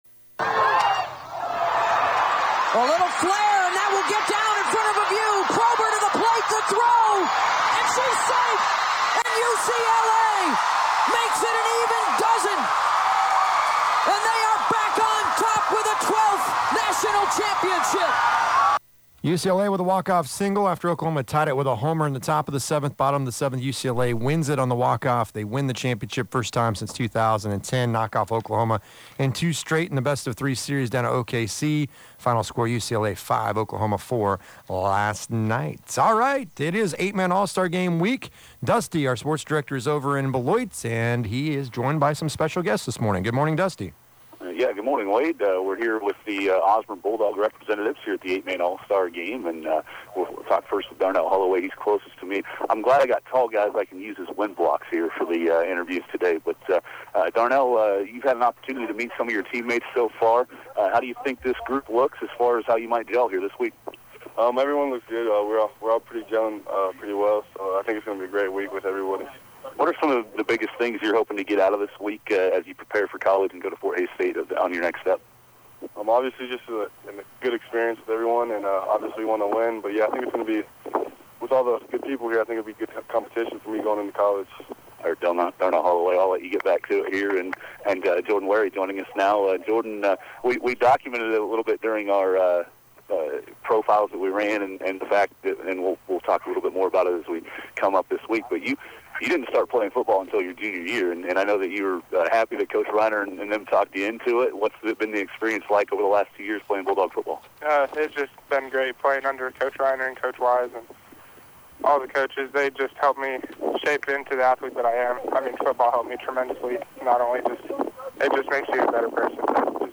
Interview the Osborne participants. Royals complete day 2 of the draft. NBA finals game 3 preview.